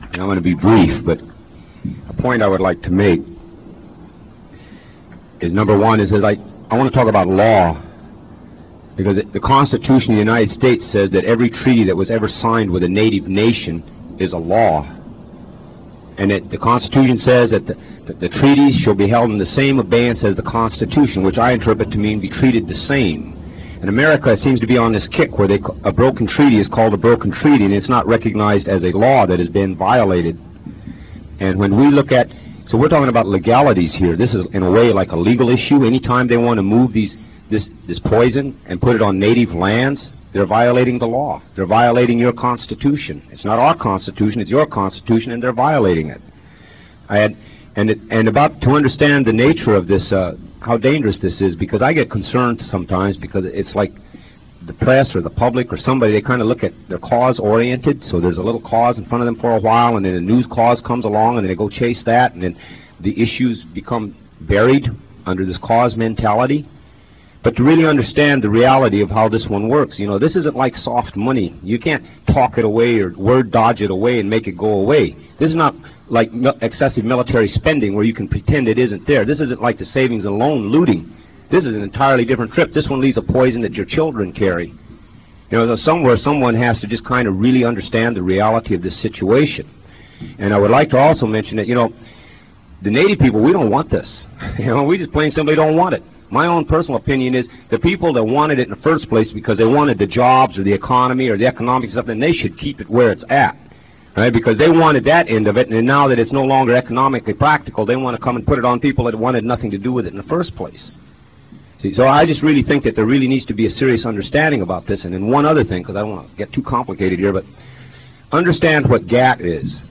lifeblood: bootlegs: 1997-09-24: honor the earth press conference - washington, d.c.
09. press conference - john trudell (2:47)